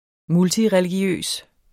Udtale [ ˈmultiʁεliˌgjøˀs ]